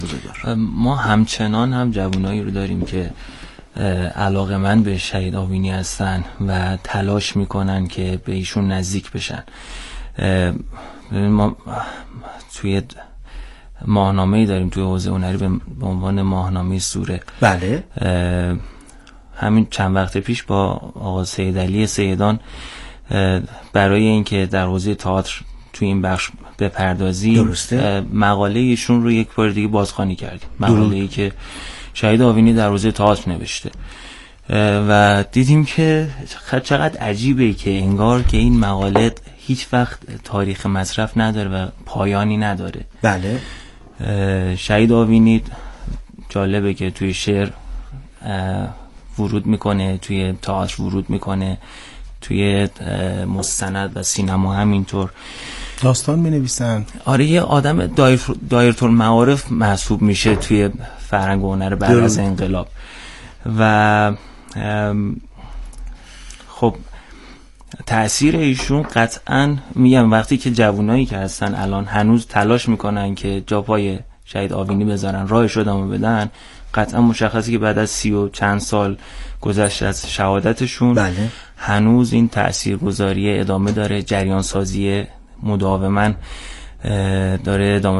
میزگرد تعاملی ایکنا